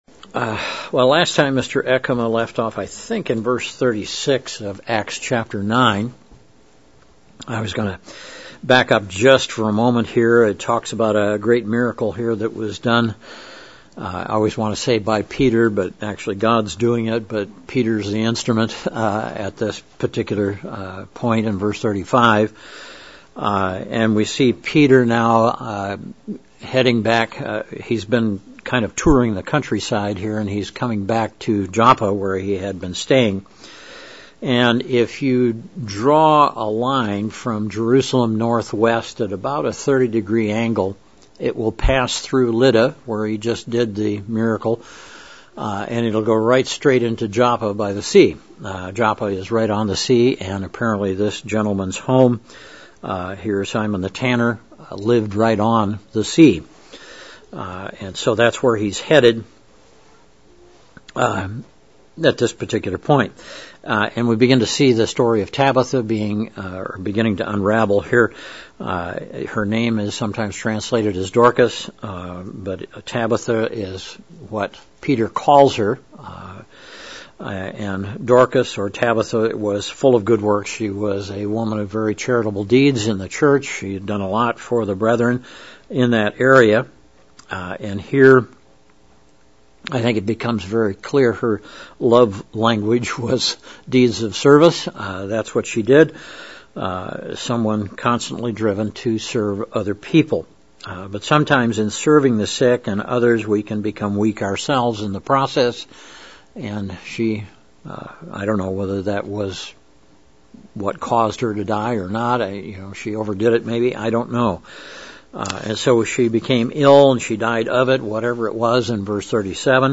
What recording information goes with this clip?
Given in Central Illinois